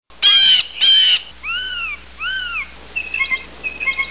Le geai | Université populaire de la biosphère
il cocarde, frigotte, frigulote, fringote, gajole, jase
geai-bleu.mp3